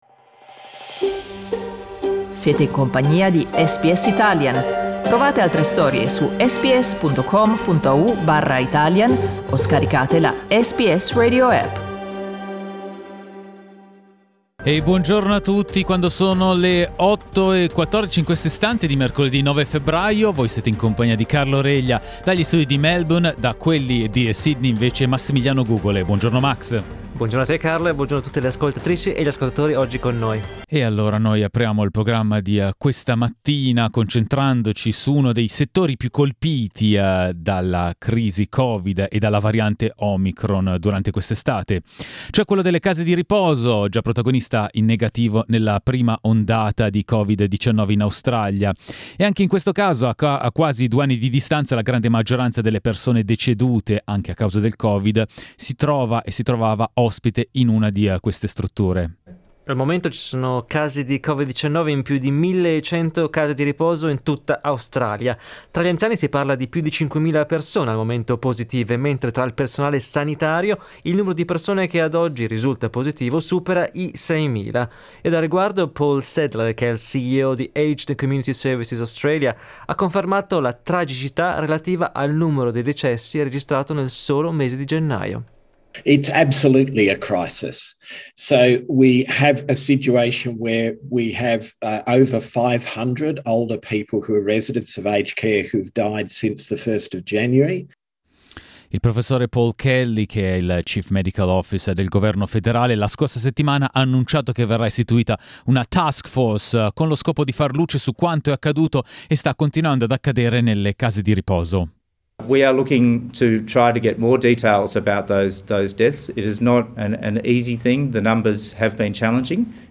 Ascolta l'inchiesta di SBS Italian sulla crisi nel settore della terza età.